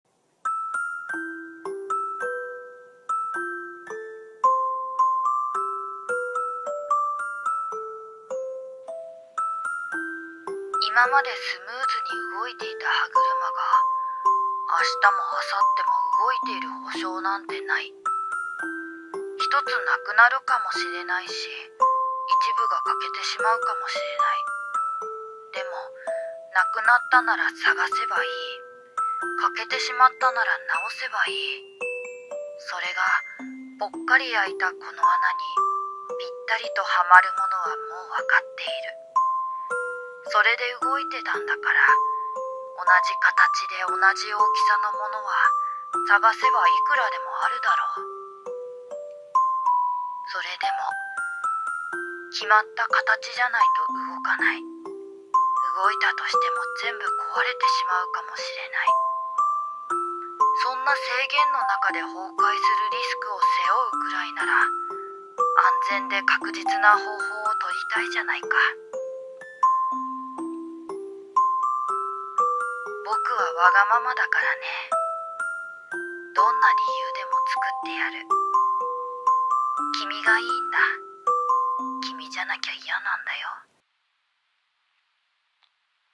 【一人声劇】壊れても 崩れても